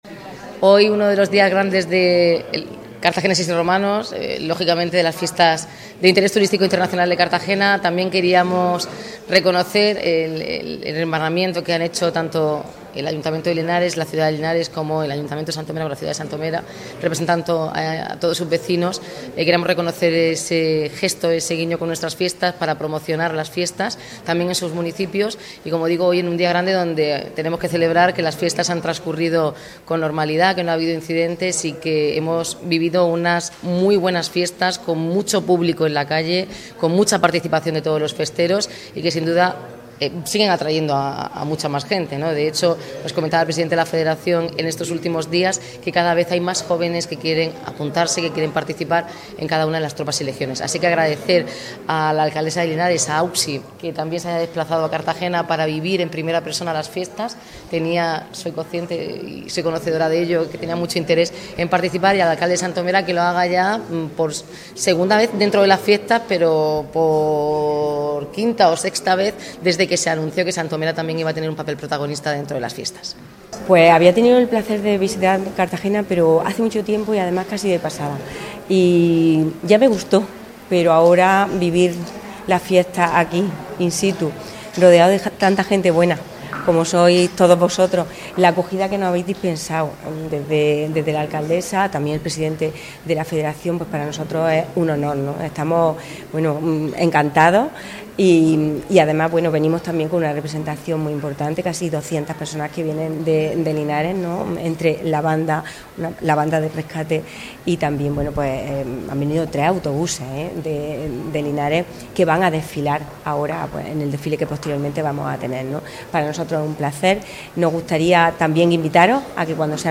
Enlace a Declaraciones de las Alcaldesas de Cartagena y Linares, y el Alcalde de Santomera
El acto ha tenido lugar en el Palacio Consistorial, donde las autoridades, acompañadas de miembros de sus equipos de gobierno, se han intercambiado obsequios y palabras de agradecimiento por la cercanía y trato recibido durante estas actividades de hermanamiento.